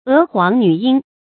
娥皇女英 é huáng nǚ yīng 成语解释 娥皇、女英：传说唐尧的两个女儿，均嫁给虞舜为妻。